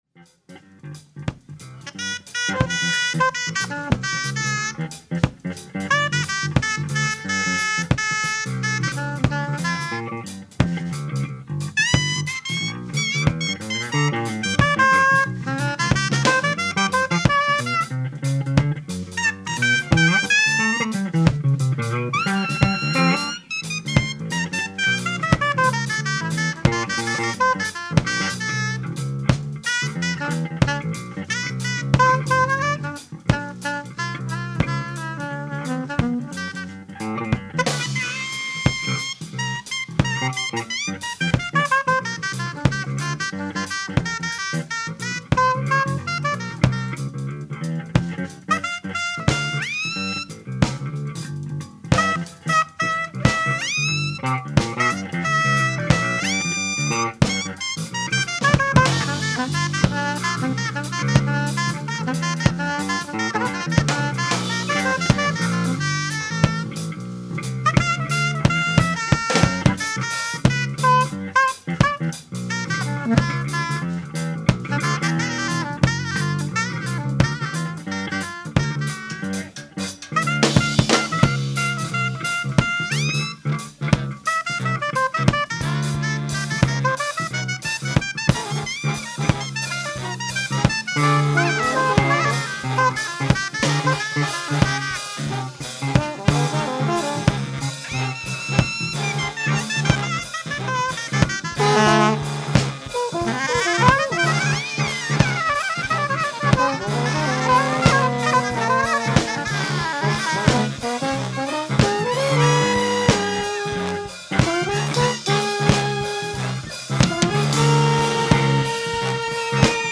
ライブ・アット・ロンスター・カフェ、ニューヨーク 01/15/1985
※試聴用に実際より音質を落としています。